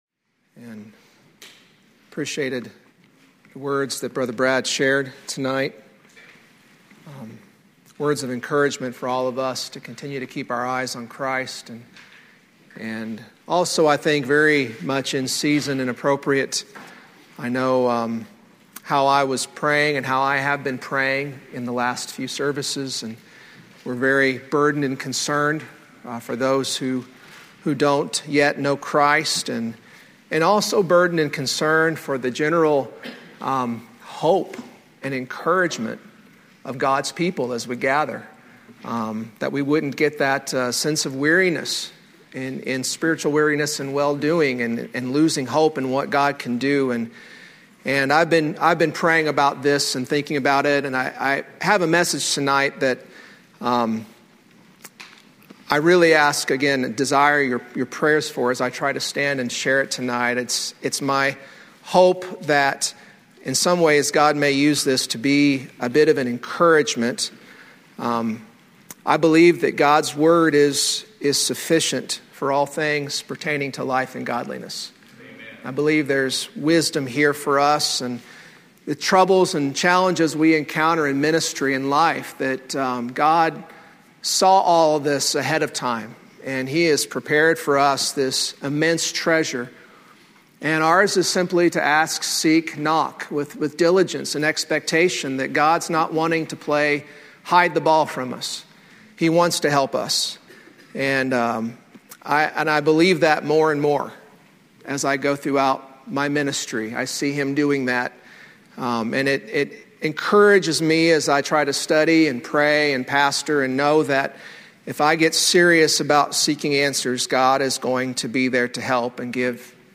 Thursday night sermon from the 2004 session of the Old Union Ministers School.